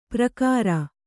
♪ prakāra